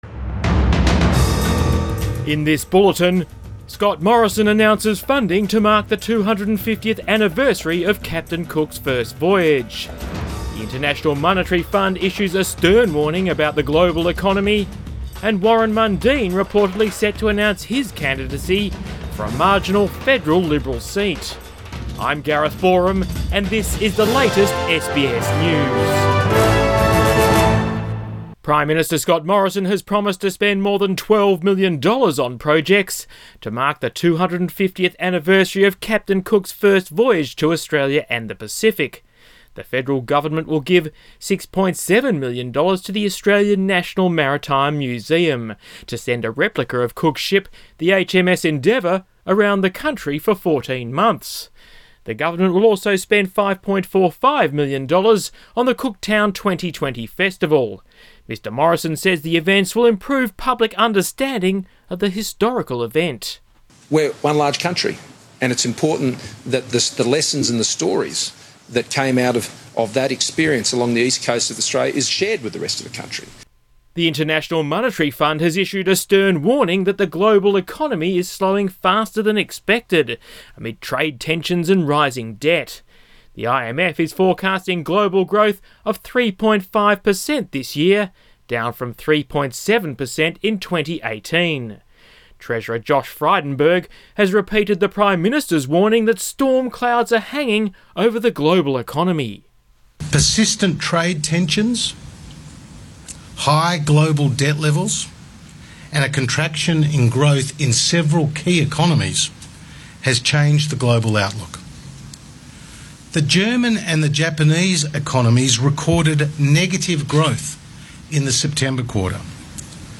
PM bulletin 22 January